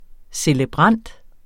celebrant substantiv, fælleskøn Bøjning -en, -er, -erne Udtale [ seləˈbʁɑnˀd ] Oprindelse jævnfør celebrere Betydninger 1.